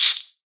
minecraft / sounds / mob / silverfish / hit2.ogg
hit2.ogg